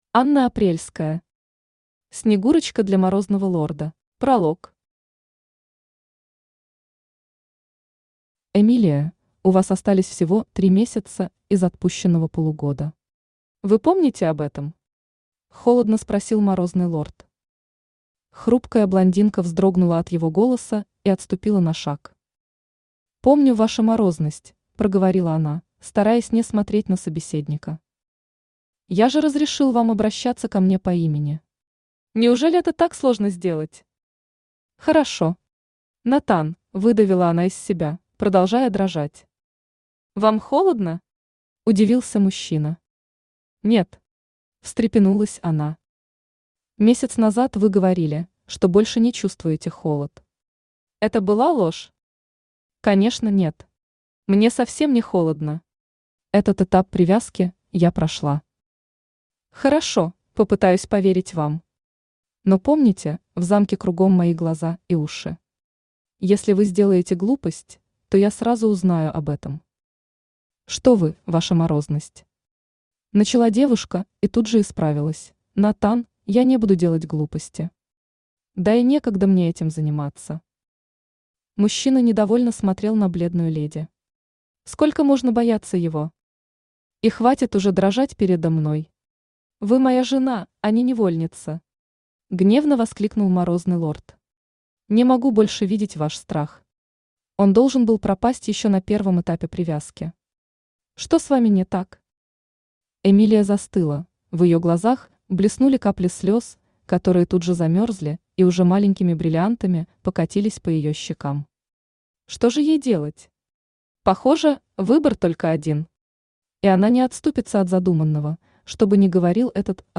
Аудиокнига Снегурочка для Морозного лорда | Библиотека аудиокниг
Aудиокнига Снегурочка для Морозного лорда Автор Анна Апрельская Читает аудиокнигу Авточтец ЛитРес.